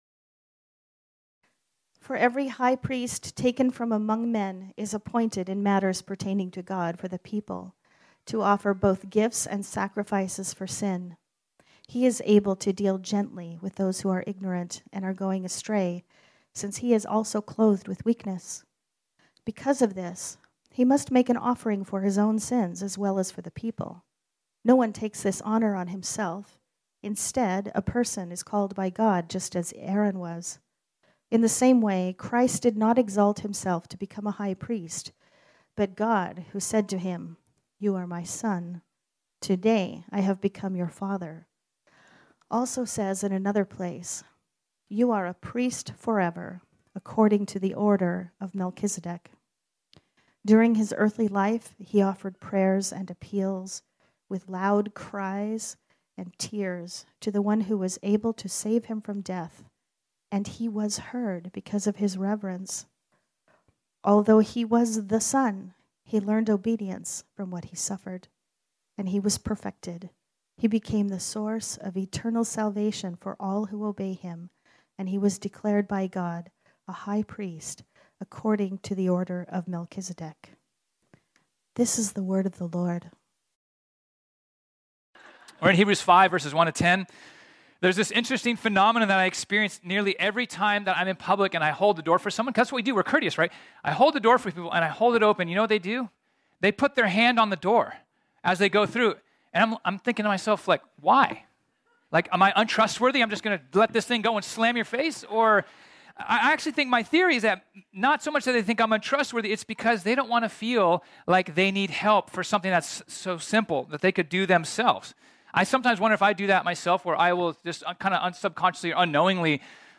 This sermon was originally preached on Sunday, November 6, 2022.